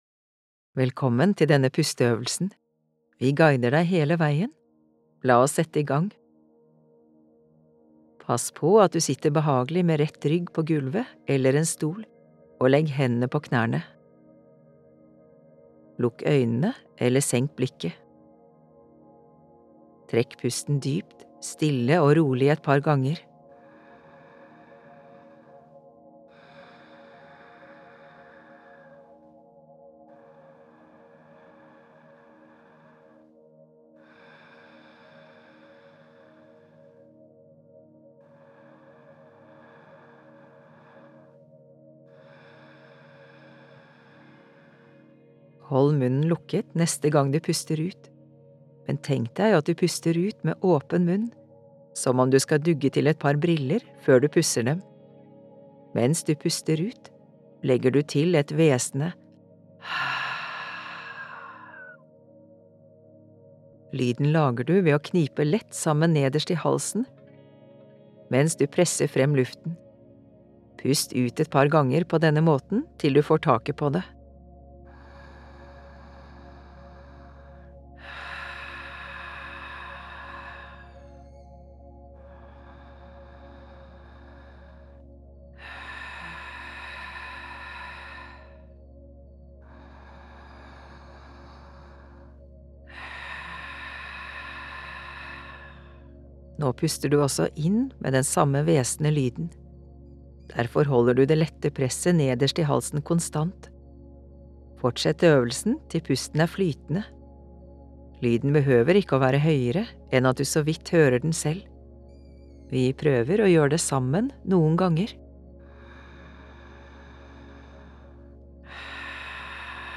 Det seirende åndedrettet – pusteøvelse med lydguide
• Først får du en intro til øvelsen.
• Deretter får du 3 min til å fortsette pusteøvelsen i ditt eget tempo.
• Til slutt får du 1 min til å kjenne på virkningen av øvelsen.